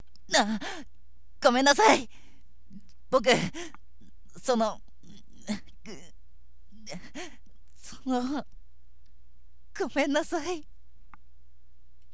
何度も何度も謝る少年